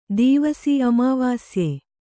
♪ dīvasi aāvāsye